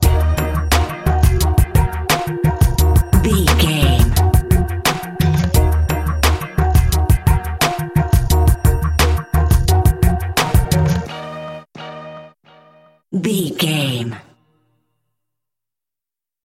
Uplifting
Aeolian/Minor
E♭
drum machine
synthesiser
percussion